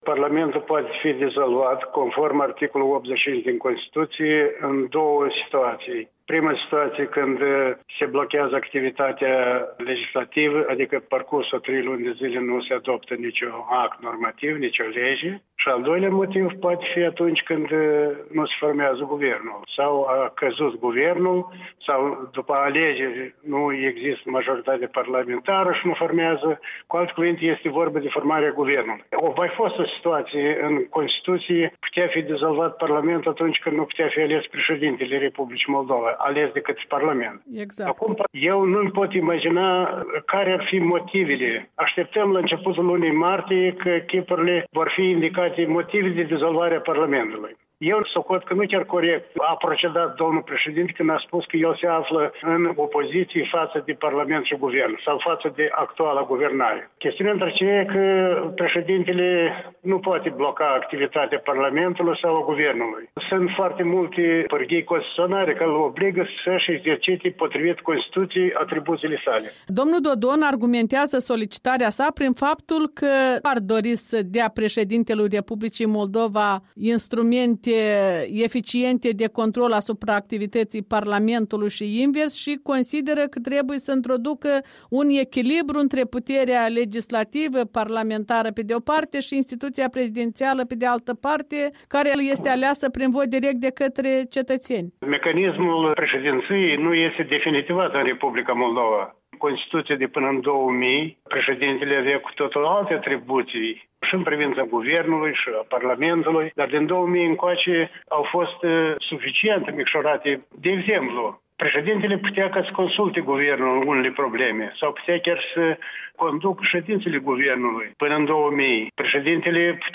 Interviu cu Victor Pușcaș